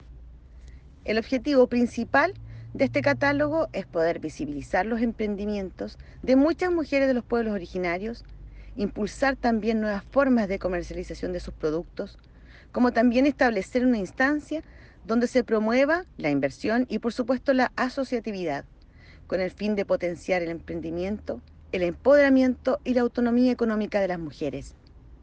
CUÑA-01-DIRECTORA-REGIONAL-SERNAMEG-.mp3